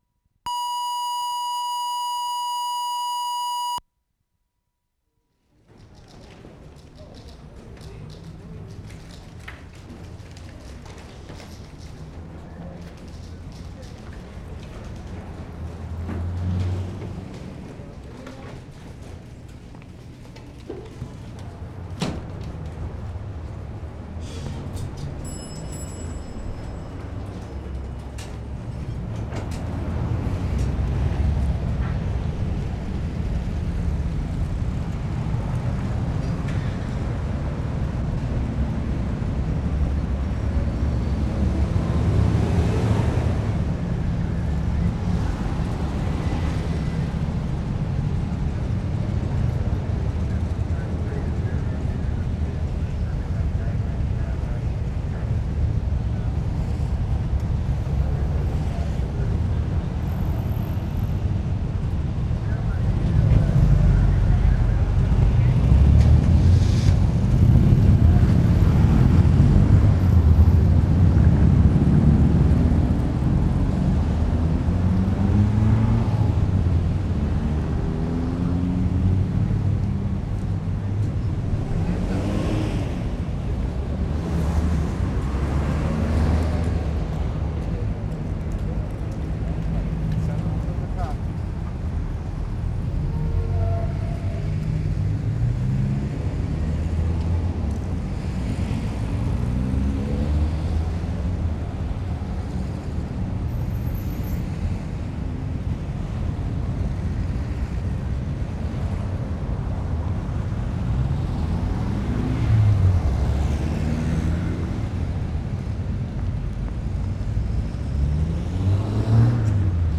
DOWNTOWN SOUNDWALK Feb. 12, 1973
TRAFFIC AND B.C. HYDRO HORN 8'40"
2. Ambient, loud brutal traffic noise outside the library, waiting for the noon horn (B.C. Hydro). Take begins inside library at check-out counter.
0'25" exit to street.
0'55" in traffic: motorcycle and police radio voice. Ambient traffic to end.
7'00"-7'30" loud trucks.
8'05" B.C. Hydro horn.